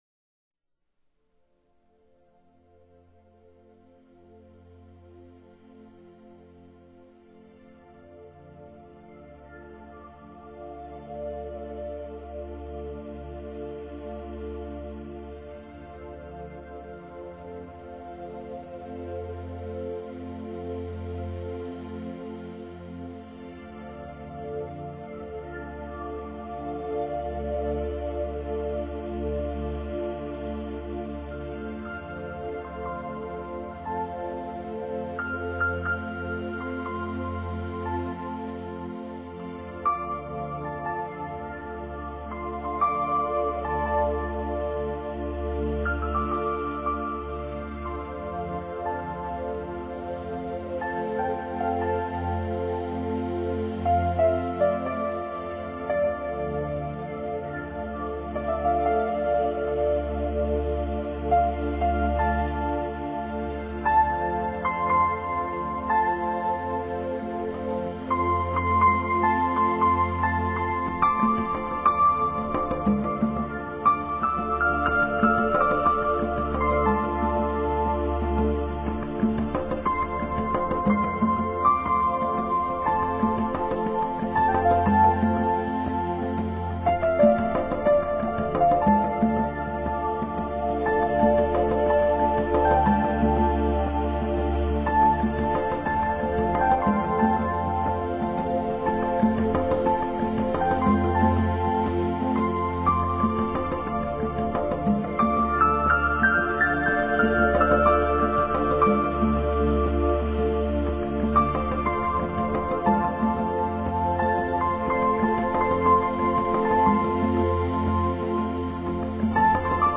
钢琴、合成器，手鼓营造出来的气氛，让人感觉似飘荡在虚空中，心情得到立刻的放松[/light]